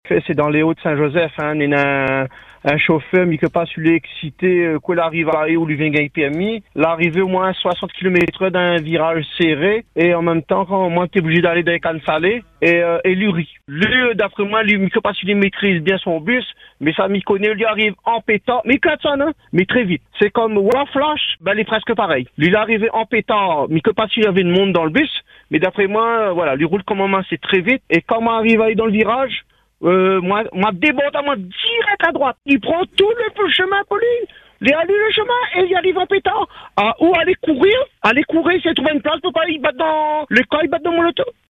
Encore sous le choc, le conducteur témoin raconte la scène et dit avoir eu très peur, estimant que dans ce secteur des hauts, la prudence est indispensable, notamment en raison de la configuration de la route et du manque de visibilité dans certains virages.